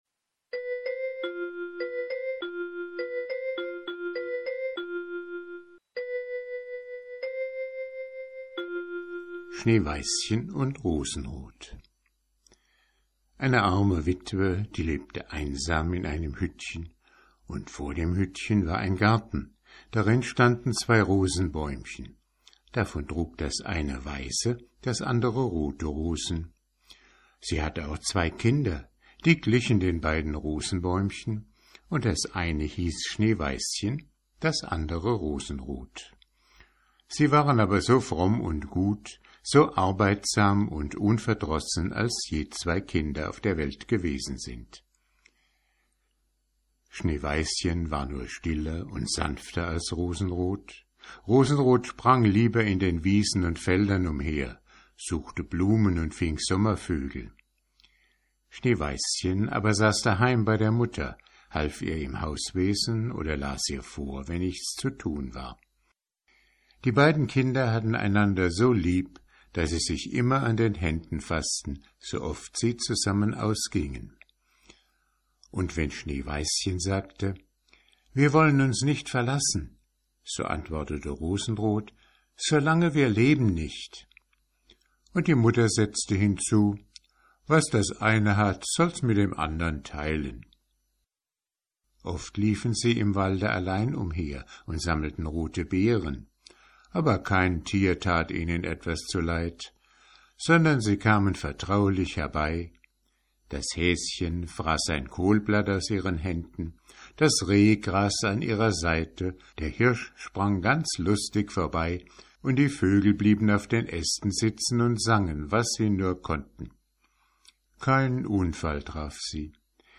Vorlesezeit 18 min ✓ Alle Grimm und Andersen Märchen in Originalfassung ✓ Online Märchenbuch mit Illustrationen ✓ Nach Lesedauer sortiert ✓ Mp3-Hörbücher ✓ Ohne Werbung